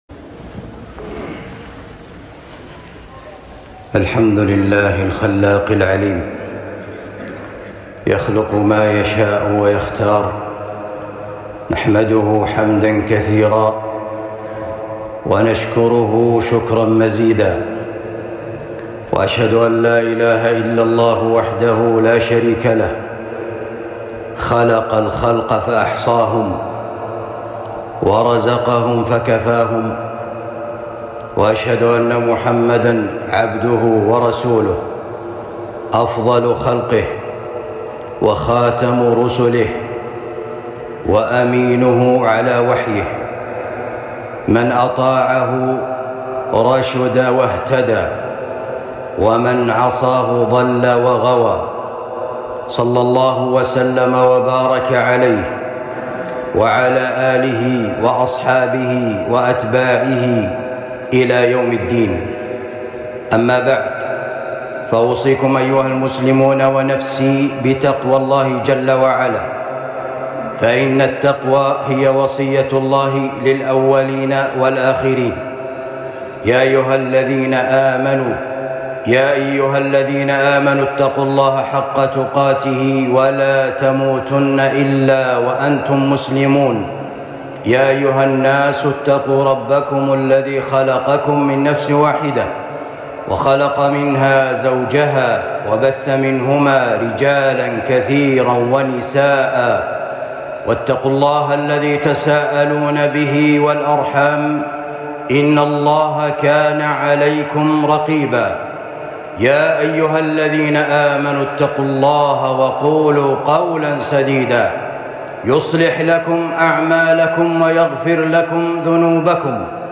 خطبة بعنوان حرمة شهر رجب